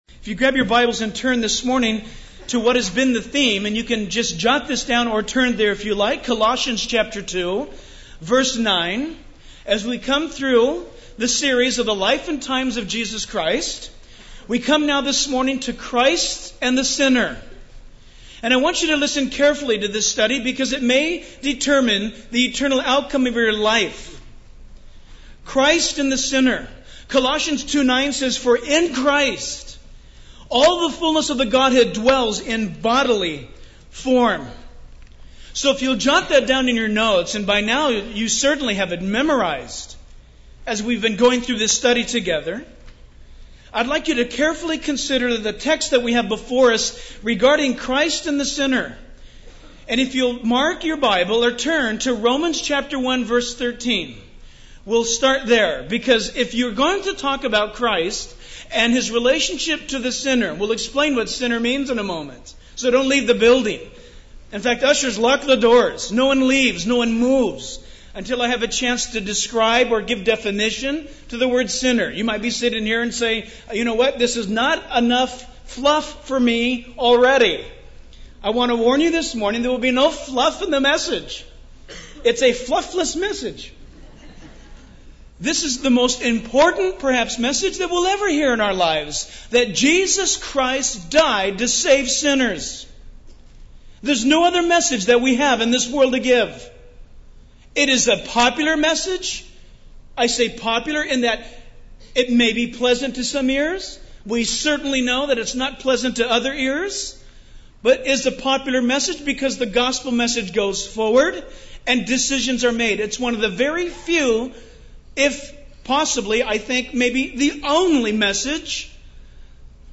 In this sermon, the speaker emphasizes the importance of getting one's theology from the Bible rather than relying on other sources. He emphasizes that God's revealed word is the ultimate truth and should be the foundation of our beliefs. The speaker also highlights the priority of the gospel, stating that it is our only remedy and should take precedence over other activities or forms of entertainment in church.